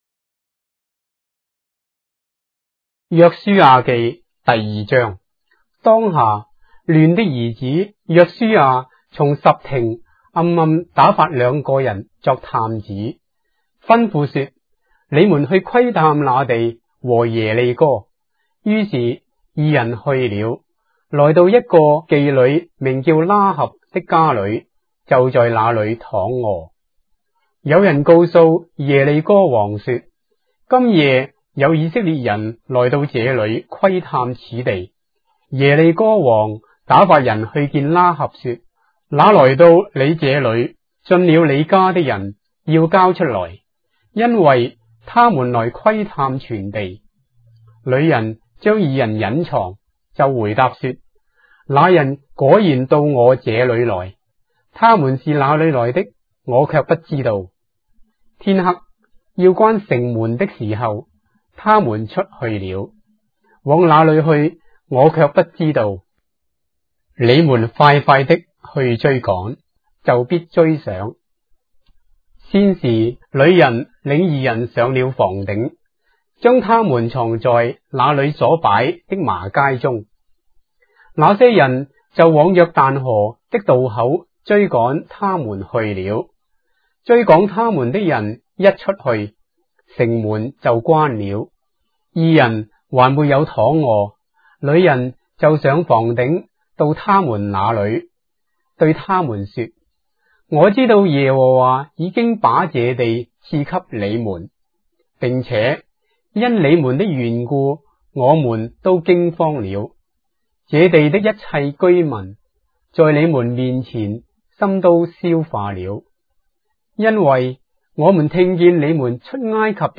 章的聖經在中國的語言，音頻旁白- Joshua, chapter 2 of the Holy Bible in Traditional Chinese